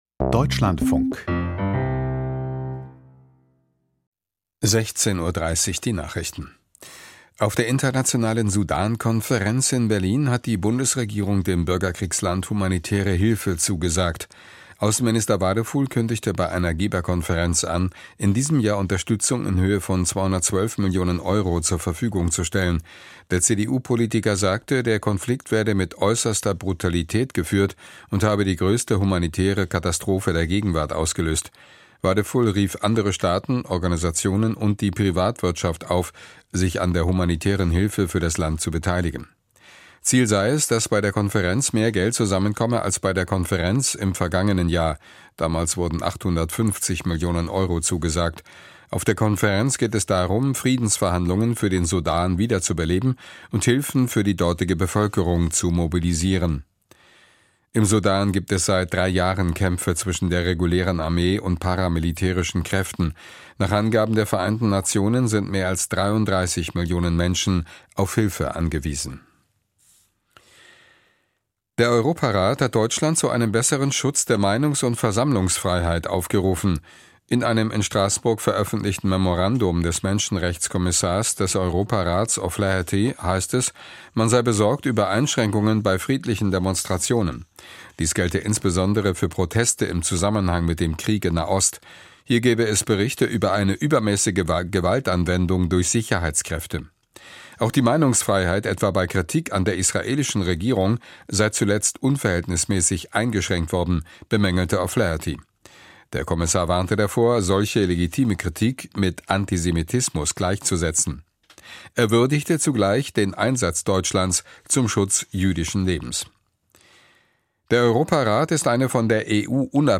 Die Nachrichten vom 15.04.2026, 16:30 Uhr
Aus der Deutschlandfunk-Nachrichtenredaktion.